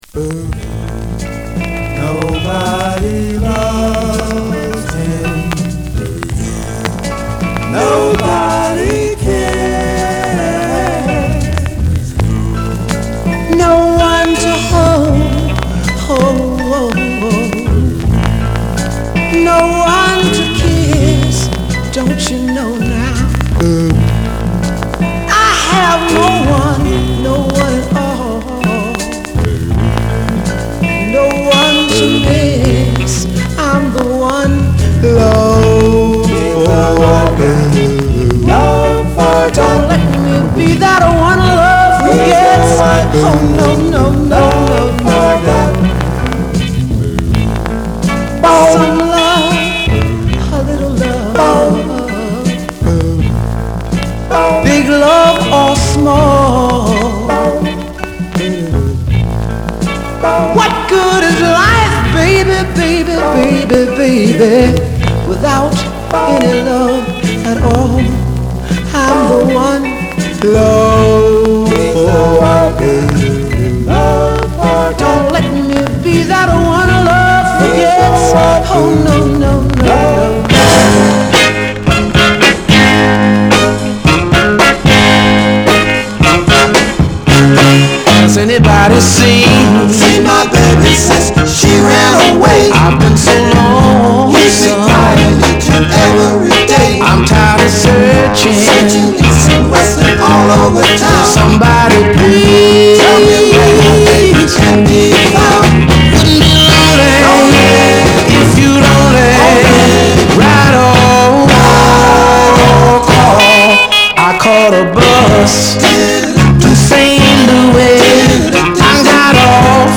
R&B、ソウル
ノーザン・ソウル・ストンパー
DOO-WOPテイストなスイート・クロスオーヴァー。
/盤質/両面やや傷あり/US PRESS